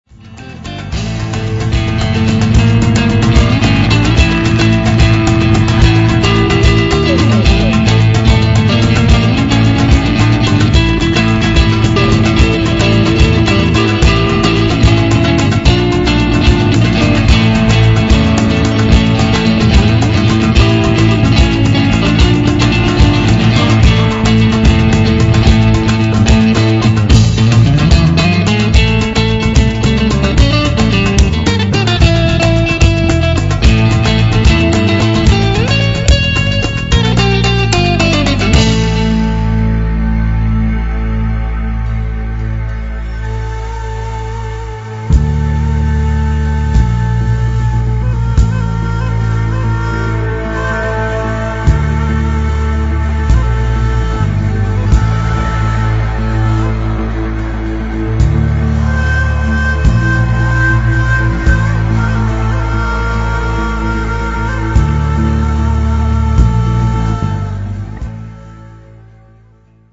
異国情緒たっぷり、７ヶ国語で歌う、トラッド・サイケ・ロック
voice
keyboards, guitar
ney, kaval, percussions, vocal
double bass, bass guitar
drums, percussions